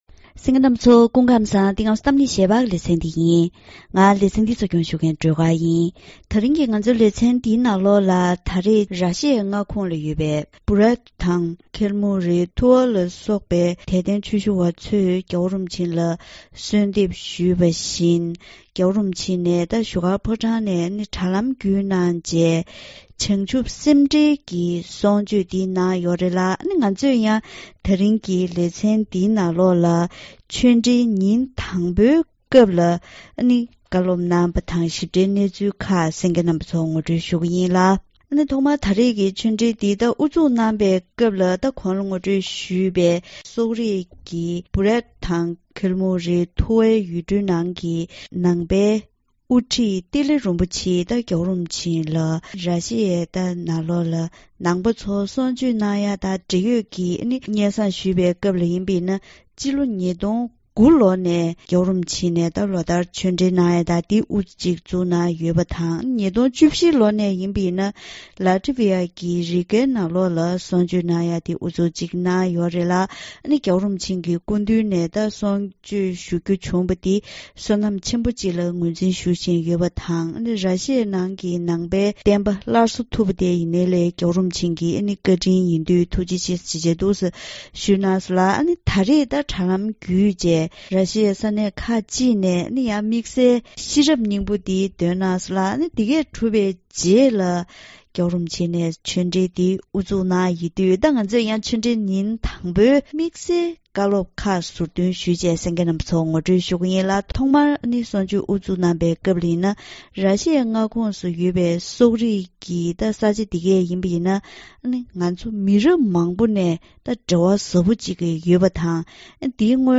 ༸གོང་ས་༸སྐྱབས་མགོན་ཆེན་པོ་མཆོག་གིས་བཞུགས་སྒར་ཕོ་བྲང་ནས་ར་ཤེ་ཡའི་དད་ལྡན་པ་ཚོར་ཆོས་འབྲེལ་གནང་སྐབས།